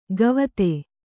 ♪ gavate